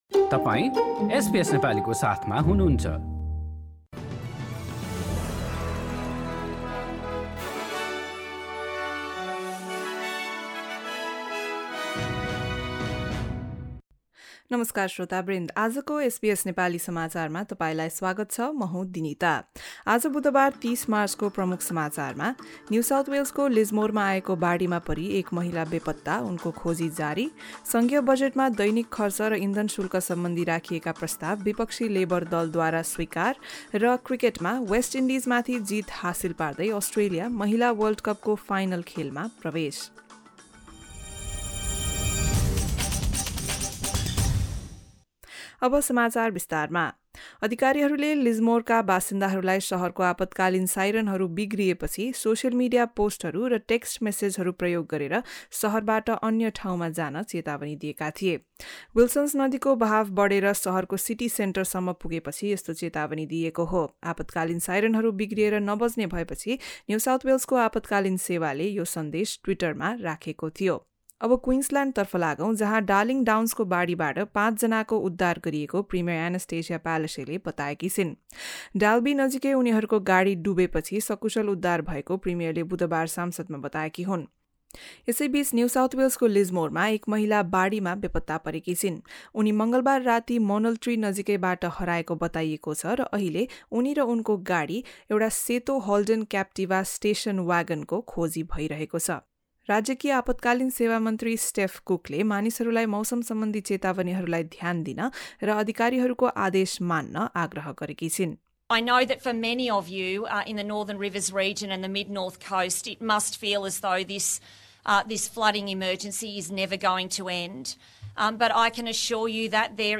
Listen to the latest news headlines from Australia in Nepali. In this bulletin, search continues for a woman lost in Lismore floods, Labor confirms it is supporting the fuel excise cut and other cost of living measures proposed by the Coalition in its budget and Australia reaches the ICC Women's Cricket World Cup final with a 157-run victory over West Indies.